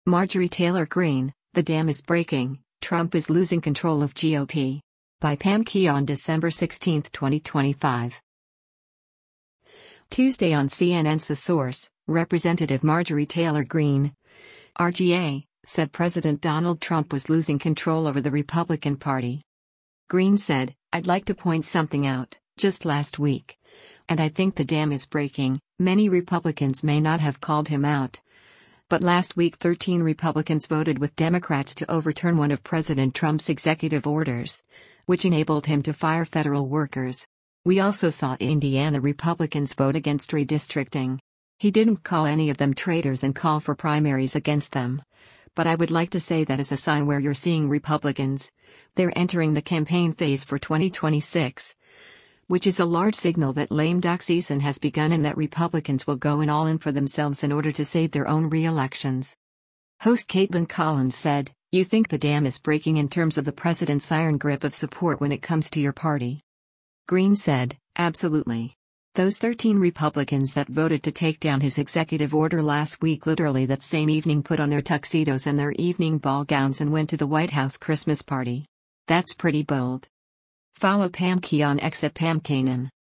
Tuesday on CNN’s “The Source,” Rep. Marjorie Taylor Greene (R-GA) said President Donald Trump was losing control over the Republican Party.